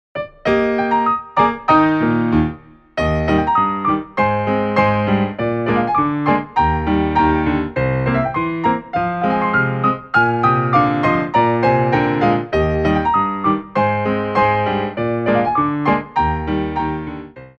2/4 (8x8)